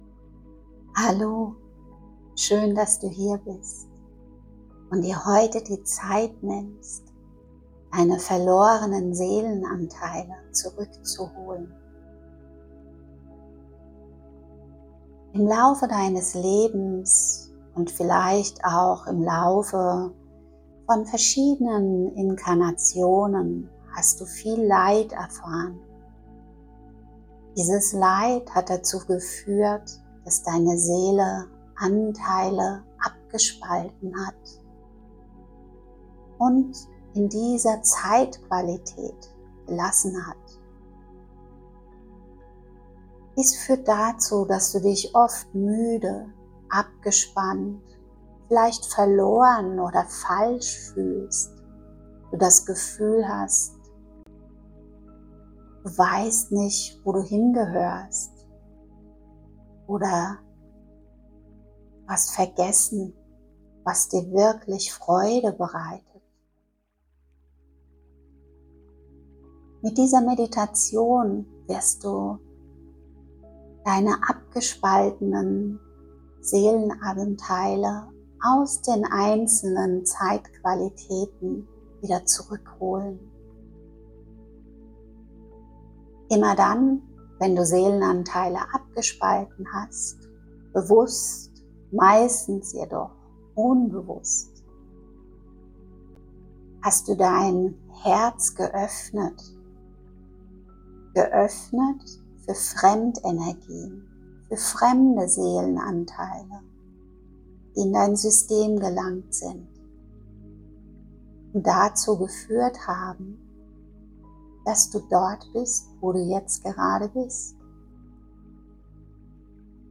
Heute schenke ich dir eine Meditation, um dich sanft von diesen Energien zu lösen und verloren geglaubte Seelenanteile zurückzuholen.